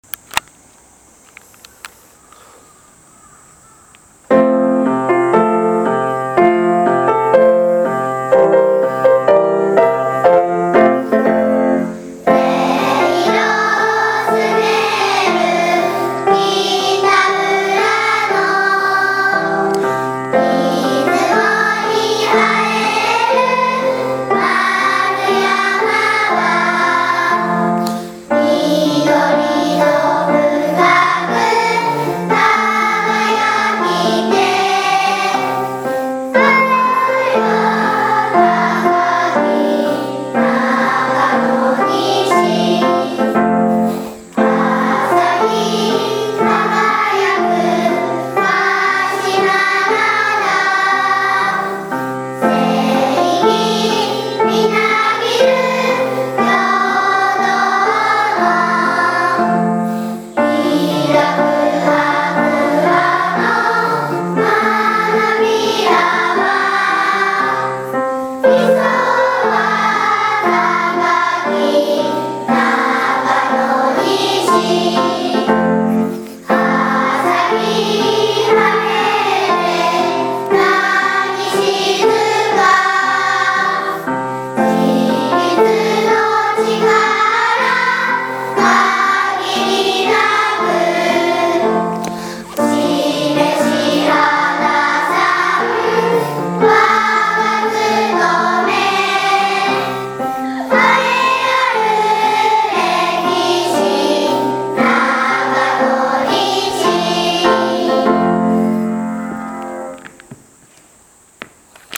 ☆ 校歌（全校合唱）